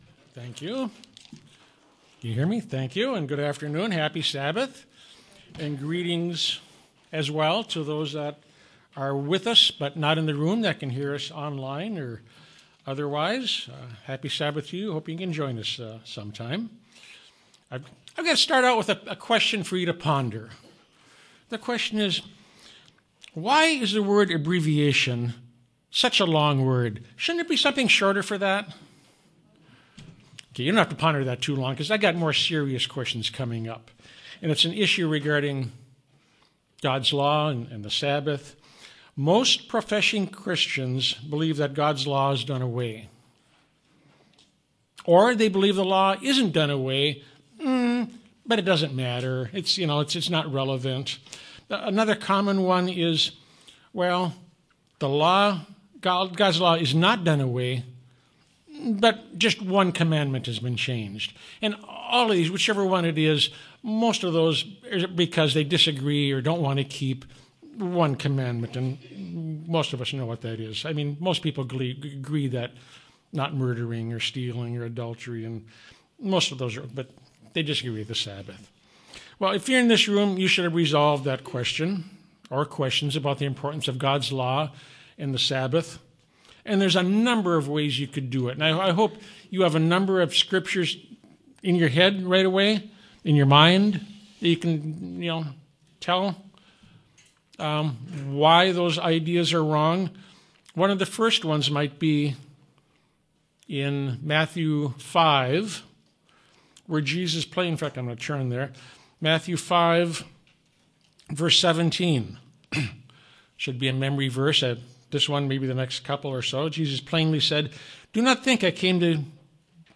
Given in Yuma, AZ